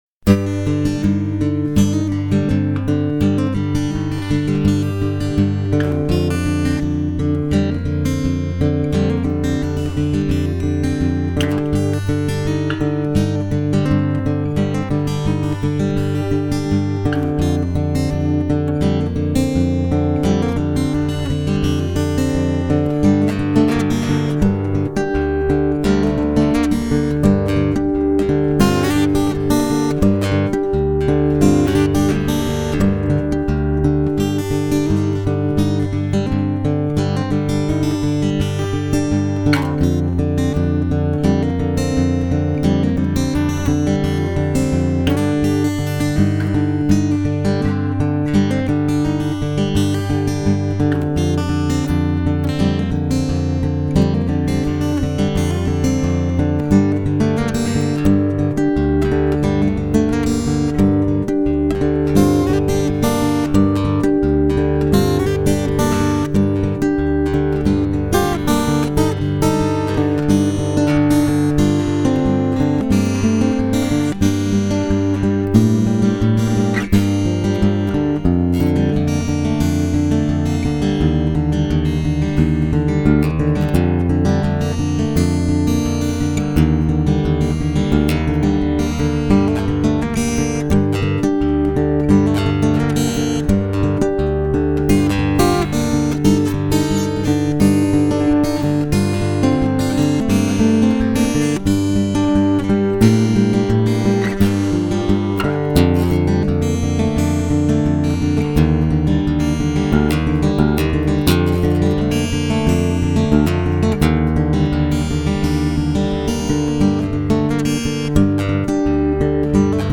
6-String Acoustic (also lead) Guitar
The instrumental